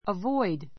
avoid A2 əvɔ́id ア ヴォ イ ド 動詞 避 さ ける avoid the rush hour(s) avoid the rush hour(s) ラッシュアワーを避ける Avoid taking sides in a lovers' quarrel.